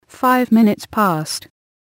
こちらは つづりを入力すると、その通りに話してくれるページです。
そこでAPR9600のAnalogInputにPCのLINEOUTを接続したところ、 適度な音量で再生ができるレベルになりました。